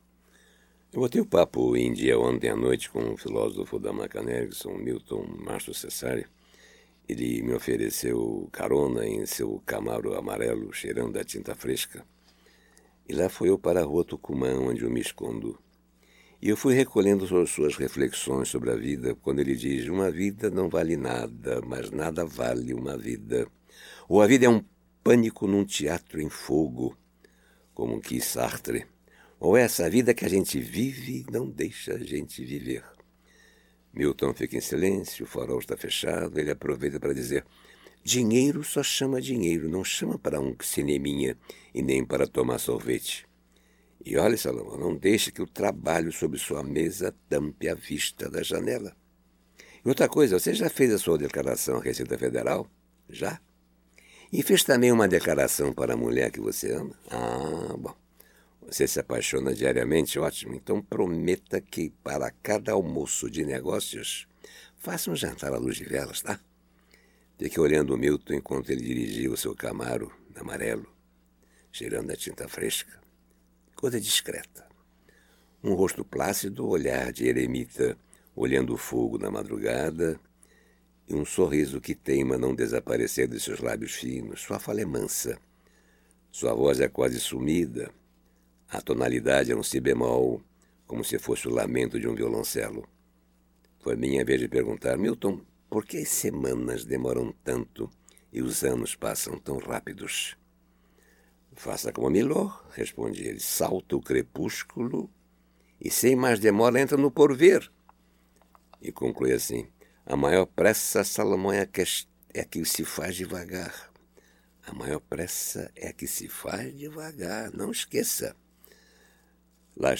Bate-PapoFilosofico.mp3